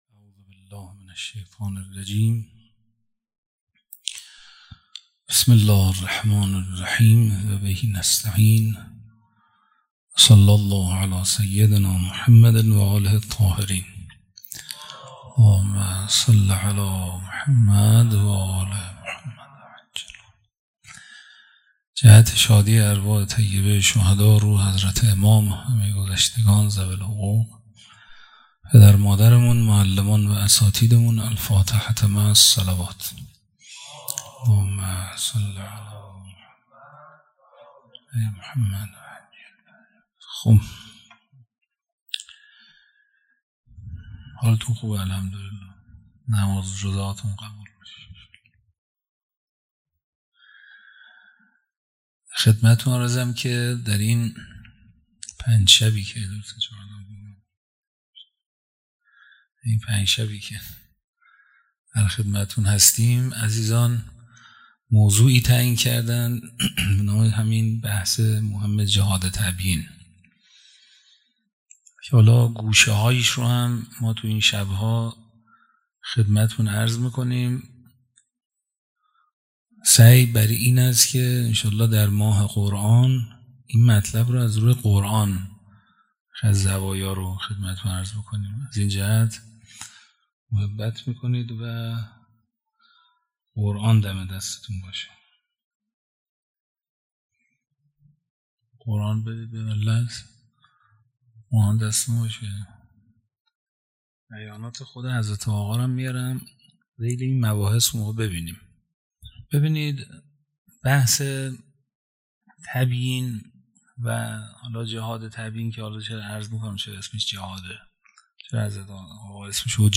سخنرانی: جهاد تبیین، حرکت پیامبرانه